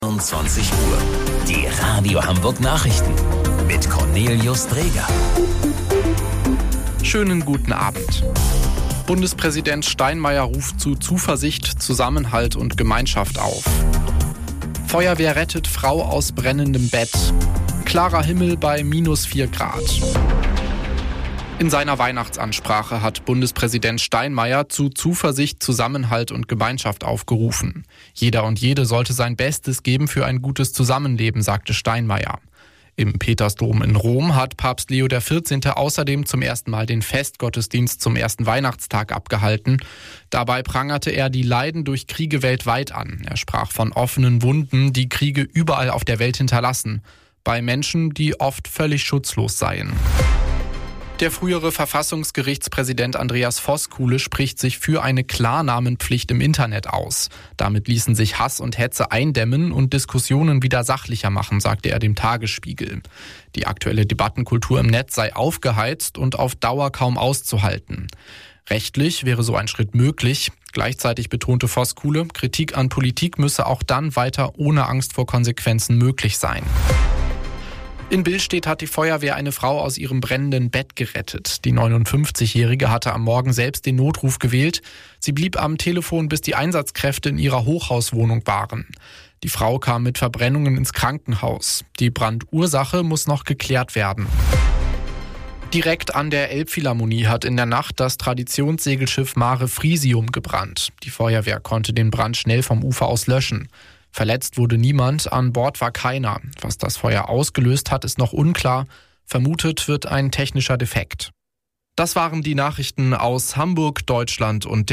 Radio Hamburg Nachrichten vom 25.12.2025 um 21 Uhr